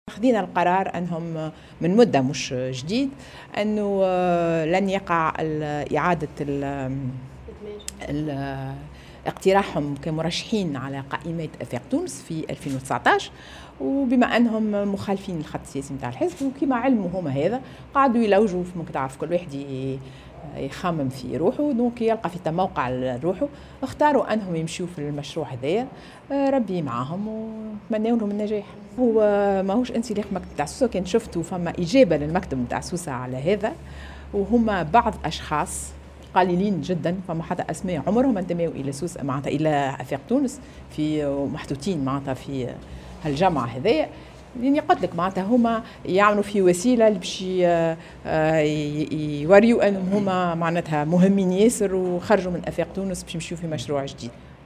وذكرت محجوب في تصريح صحفي، عقب اجتماع المكتب السياسي للحزب اليوم السبت، أن الحزب انطلق في اختيار مرشحيه لتلك الإنتخابات وقرر أن يكون حاضرا في كل الدوائر الإنتخابية.